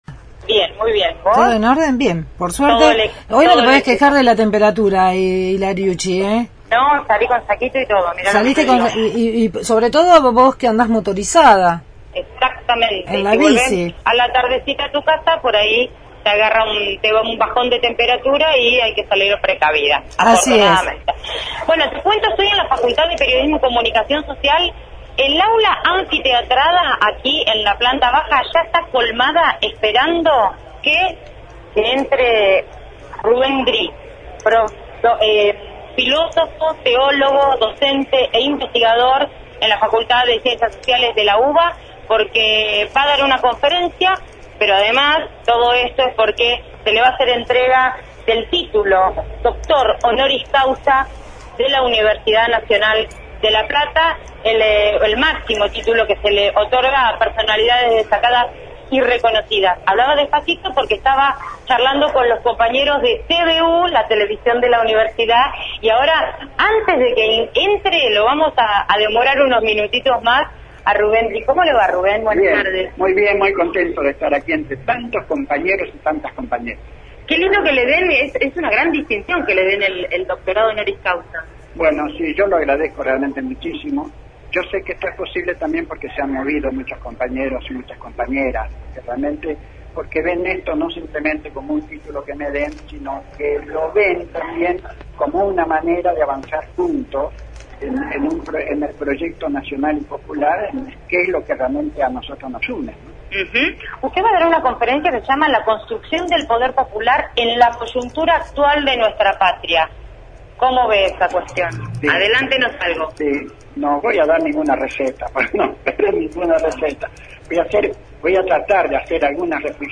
desde la Facultad de Periodismo y Comunicación Social por la entrega del Doctor Honoris Causa al filósofo y teólogo Rubén Dri.